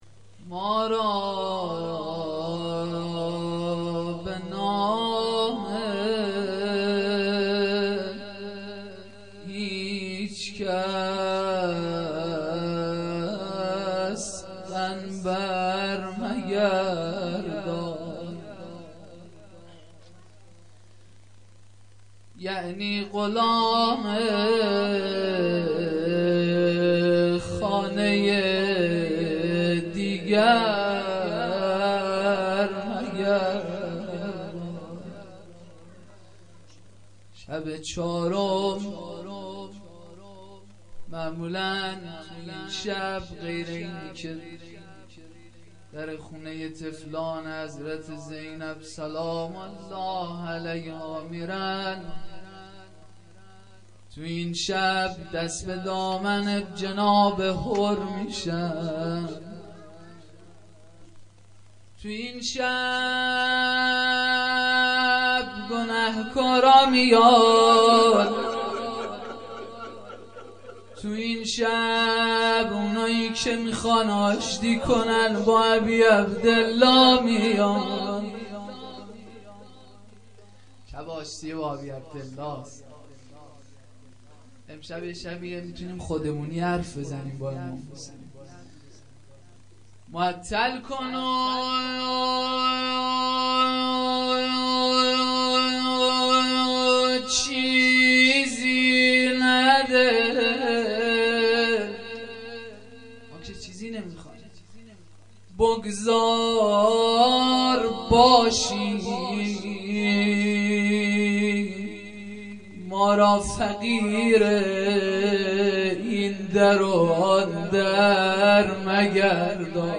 جلسه مذهبی زیارت آل یاسین باغشهر اسلامیه
روضه - شب چهارم محرم 95-01